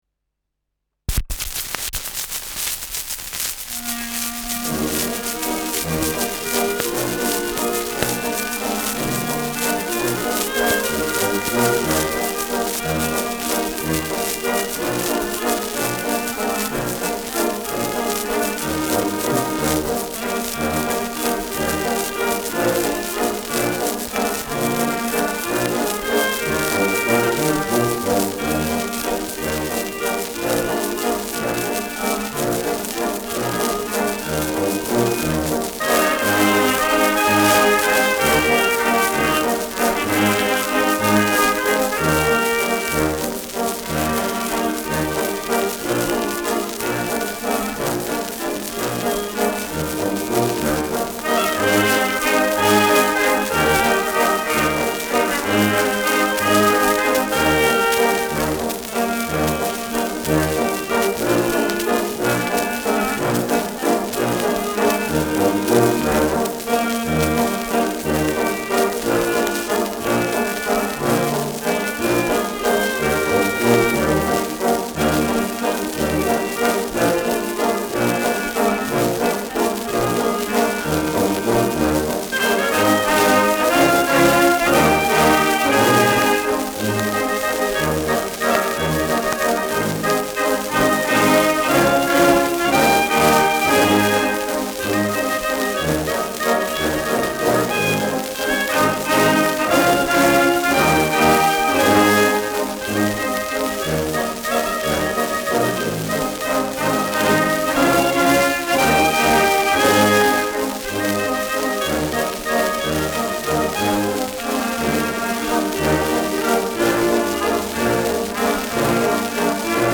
Schellackplatte
präsentes Rauschen : Knistern
[München] (Aufnahmeort)